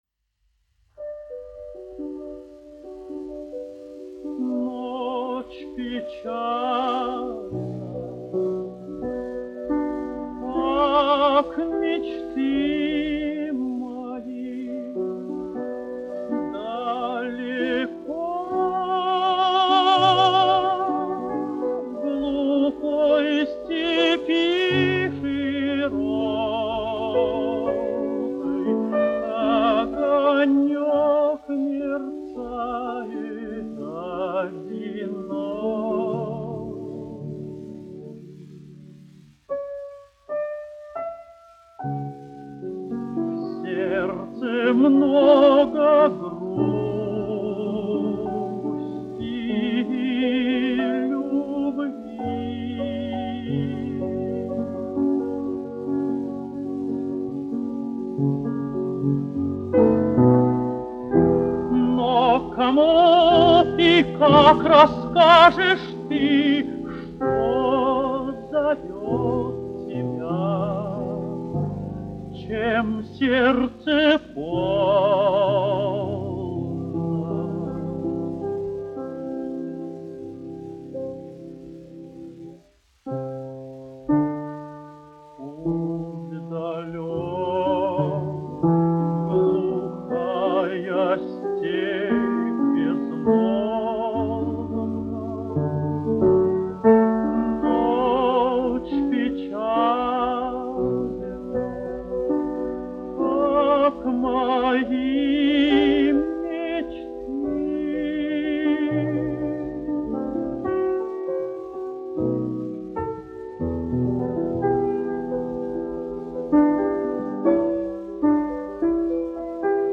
Виноградов, Георгий Павлович, 1908-1980, dziedātājs
Гольденвейзер, Александр Борисович, 1875-1961, instrumentālists
1 skpl. : analogs, 78 apgr/min, mono ; 25 cm
Dziesmas (augsta balss) ar klavierēm
Romances (mūzika)
Skaņuplate